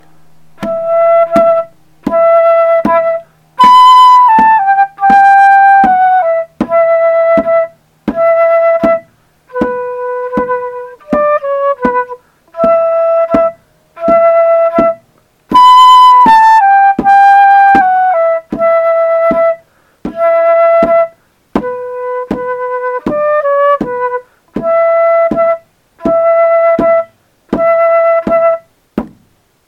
Chant Melody (no words)
Oh-din, Oh-din, Ahl-faw-thur, Vahl-faw-thur, Greem-Neer, Roo-nah-gooth, Oh-din, Oh-din, Eeuh-gur, Yal-kur, Hahr-bahrd, Gahn-glair-ee, Oh-din, Oh-din, Oh-din.